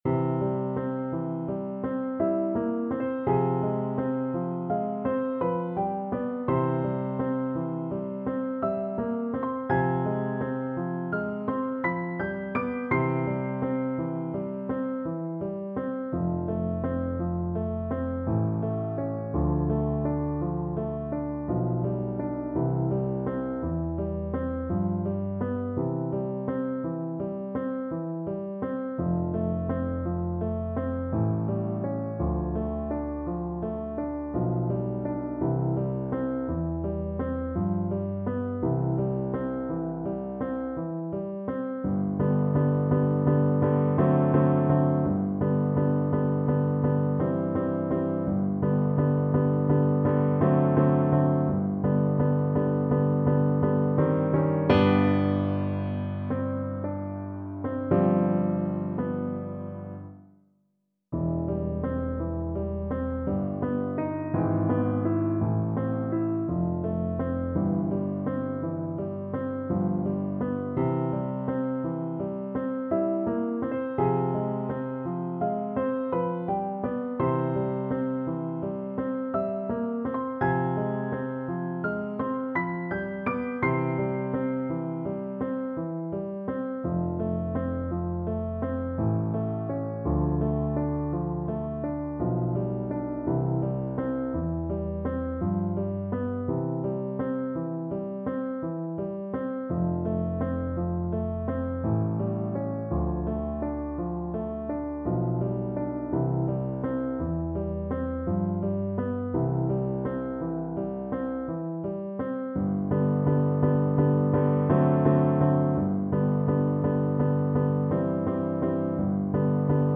Play (or use space bar on your keyboard) Pause Music Playalong - Piano Accompaniment Playalong Band Accompaniment not yet available reset tempo print settings full screen
C major (Sounding Pitch) G major (French Horn in F) (View more C major Music for French Horn )
~ = 56 Ziemlich langsam
3/4 (View more 3/4 Music)
Classical (View more Classical French Horn Music)